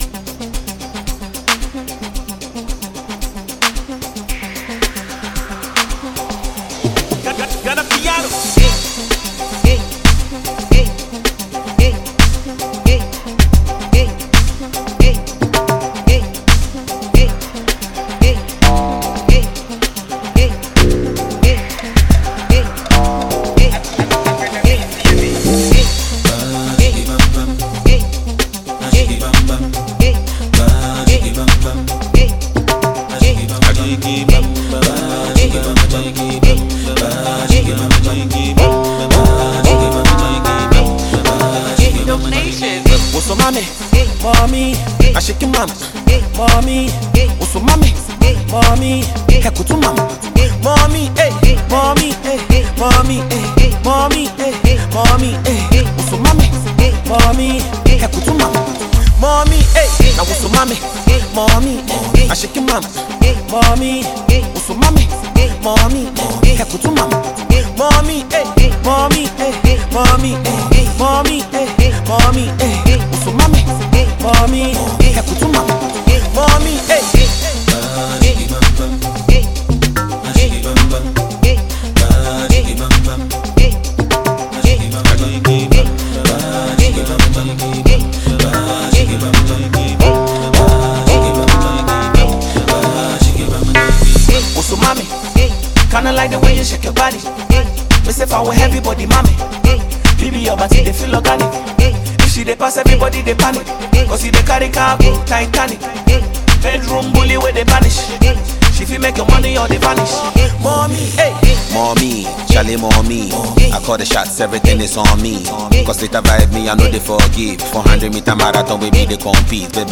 two talented Ghanaian twins
a talented Ghanaian duo.